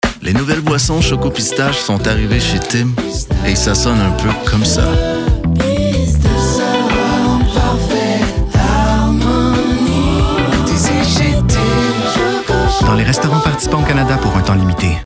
Démo Voix et Jingle (Tim Hortons) :
âge de la voix: 30 - 40 caractéristiques: voix grave, volubile, calme aptitudes en chant: oui, chanteur professionnel Démo voix :
[1]Publicis_TimHortons_ChocolatEtPistacheRADIO_15FR_RAD.mp3